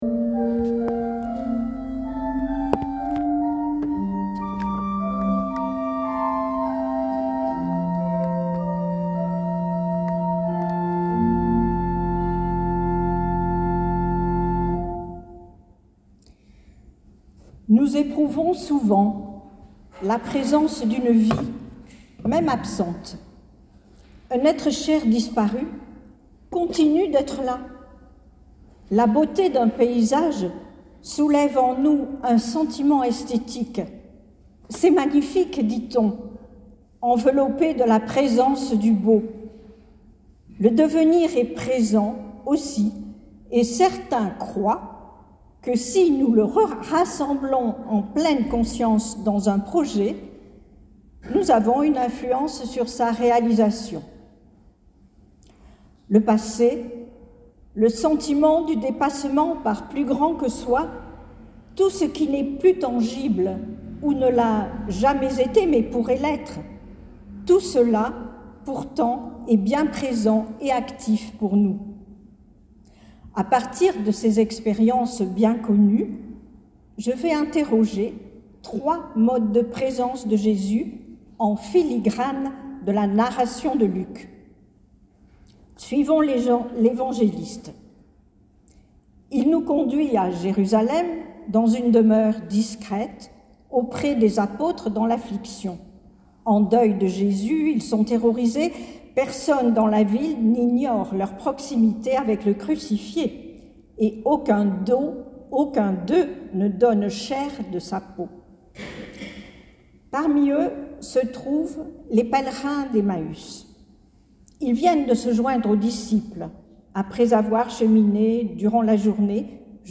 LA PRÉDICATION